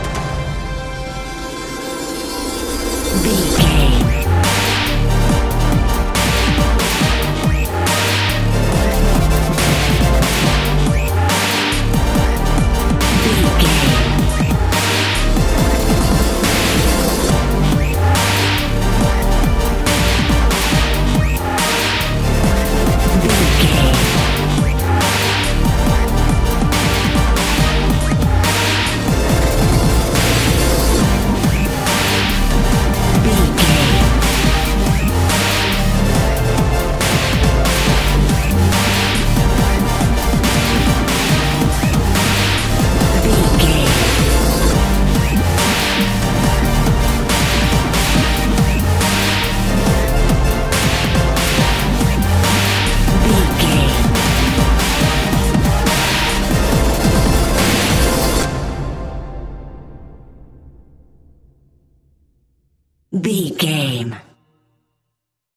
Epic / Action
In-crescendo
Ionian/Major
strings
drums
drum machine
synthesiser
orchestral
orchestral hybrid
dubstep
aggressive
energetic
intense
powerful
bass
synth effects
wobbles
heroic
driving drum beat
epic